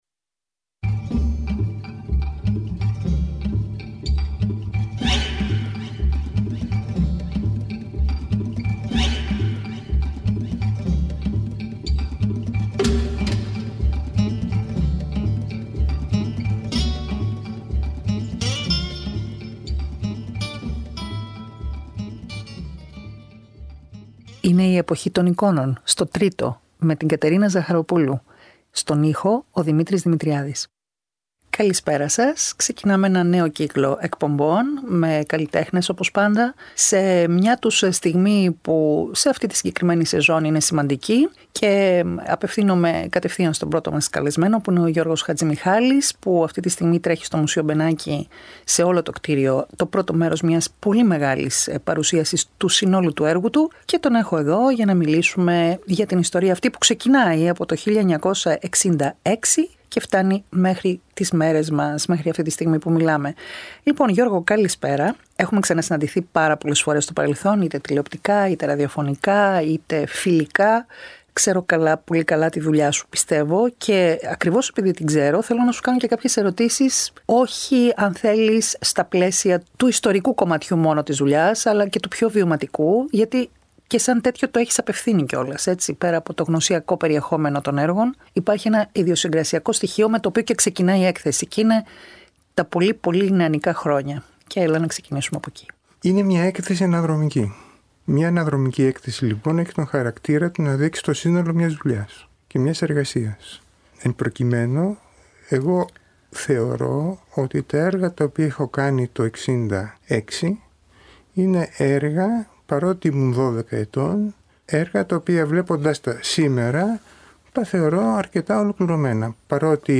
συζητά με τον καλλιτέχνη το περιεχόμενο και τις σημασίες των έργων του έτσι όπως ο ίδιος προσδιορίζει την διαδρομή τους στο χρόνο.